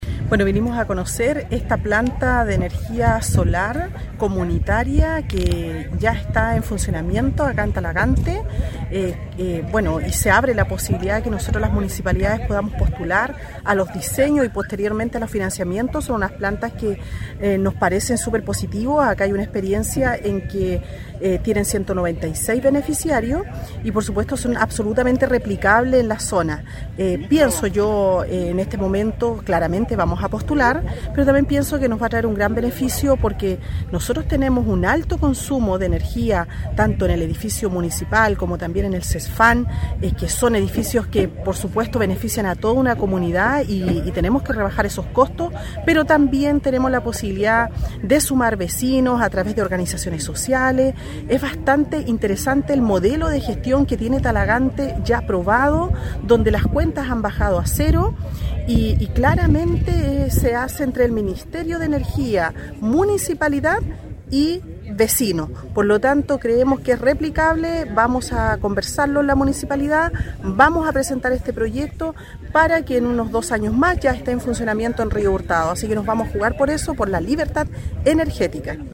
La alcaldesa Carmen Juana Olivares comenta el proyecto que es posible de replicarse en comunas como Río Hurtado.